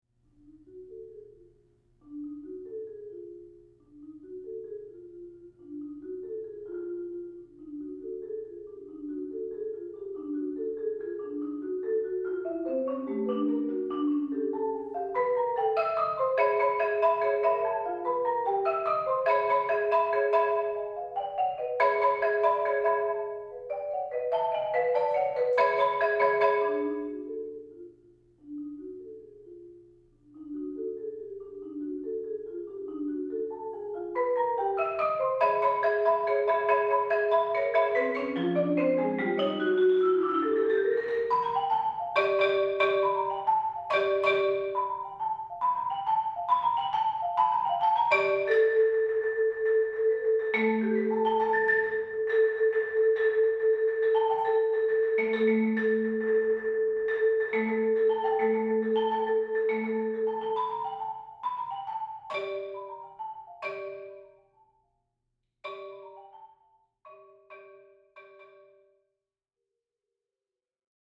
Genre: Duet for 2 Marimbas
Marimba 1 (4.3-octave)
Marimba 2 (4.3-octave)